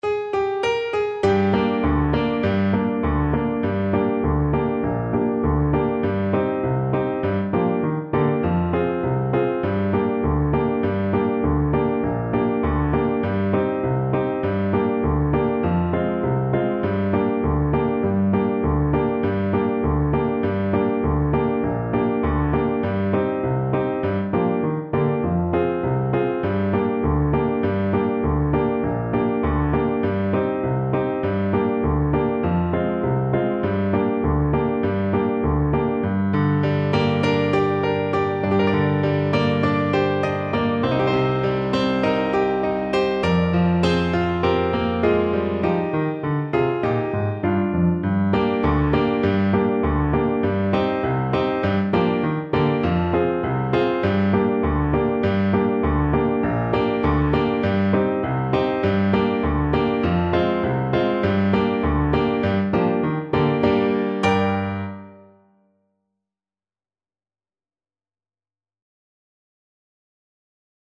Steadily =100
2/4 (View more 2/4 Music)
Traditional (View more Traditional French Horn Music)